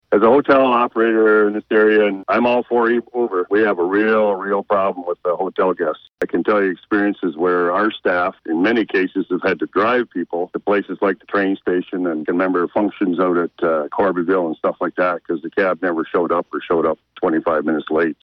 Former Quinte West Mayor John Williams also called in to comment and says it’s not just about the residents, but the visitors and tourists as well.